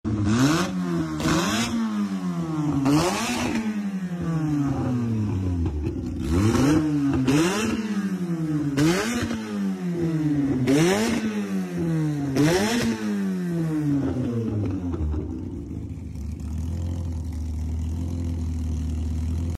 Corsa com dois motores c20xe sound effects free download